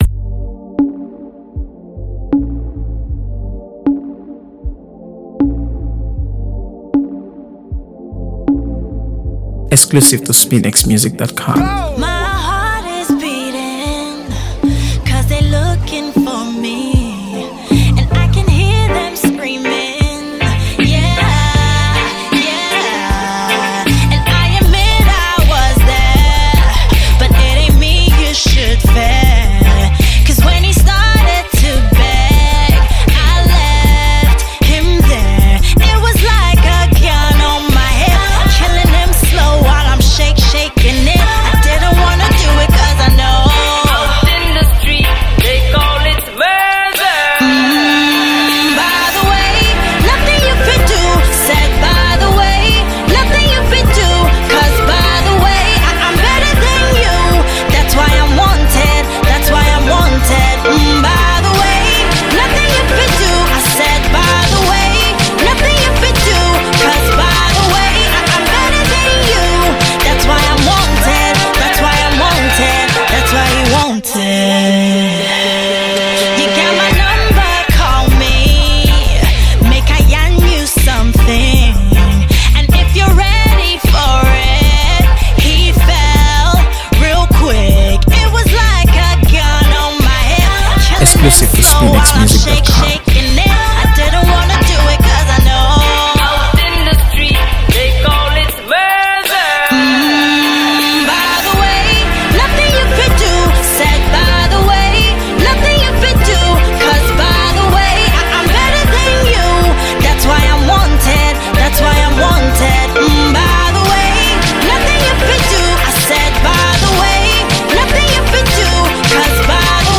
AfroBeats | AfroBeats songs
Its catchy beat